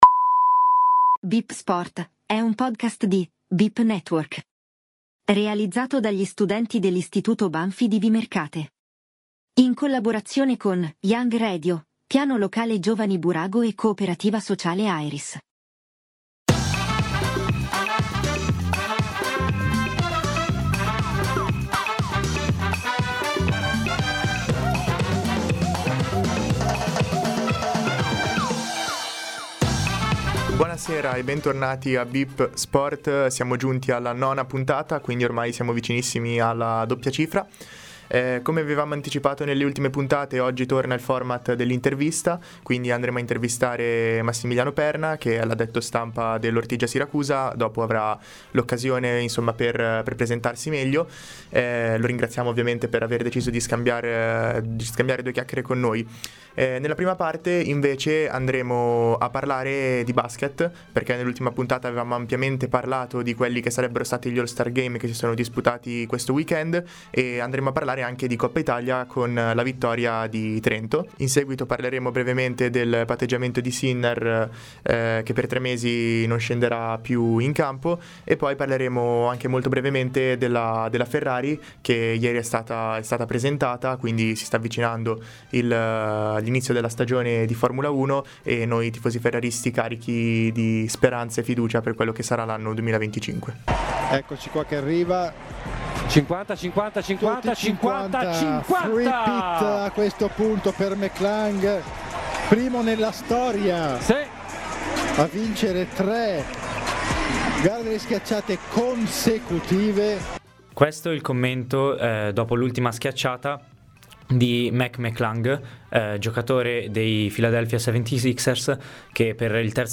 In questo episodio spazio alla Pallanuoto con l’intervista